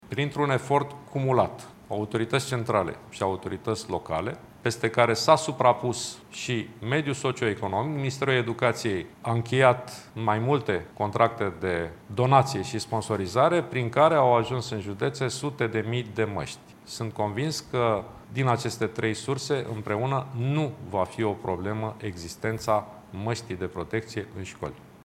Deja au ajuns în judeţe sute de mii de măşti, spune ministrul Sorin Câmpeanu: